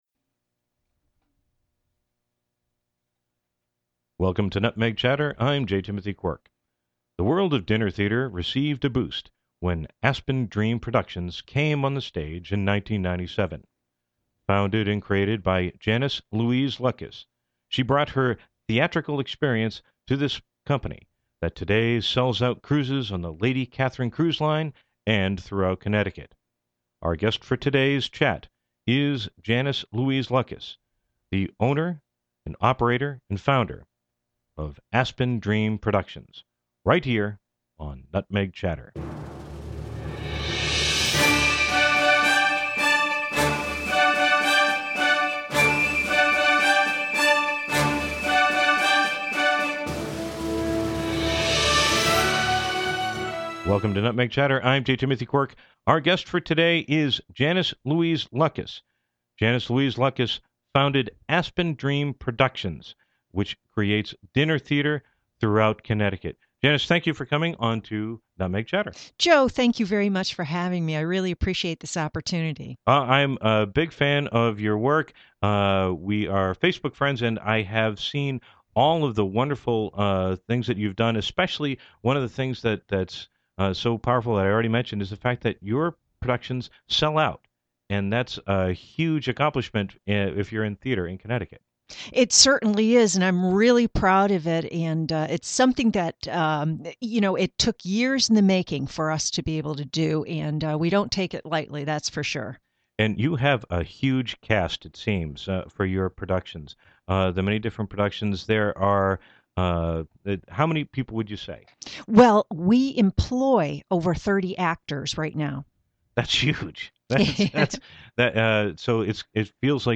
Radio Show